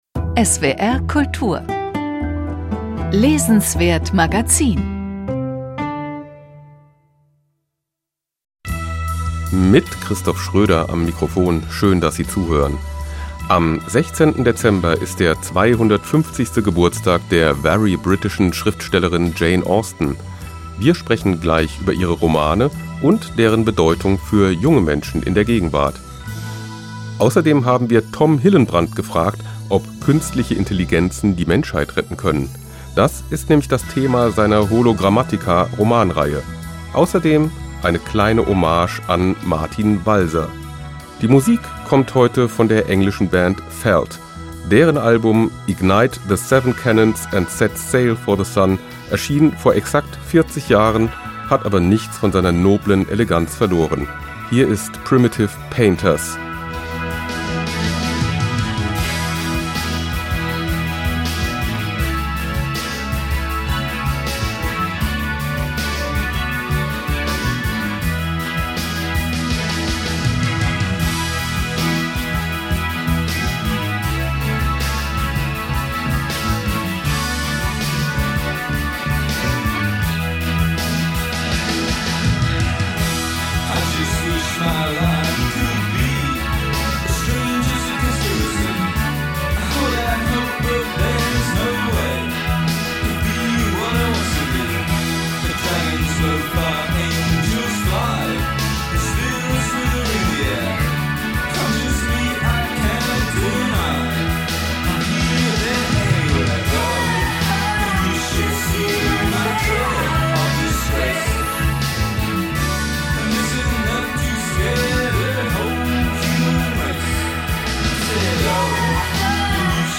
Mit neuen Büchern von Helga Schubert, András Visky und Tom Hillenbrand. Mit einem Gespräch über die Aktualität von Jane Austens Romanen und mit einer Hommage an Martin Walser. ~ SWR Kultur lesenswert - Literatur Podcast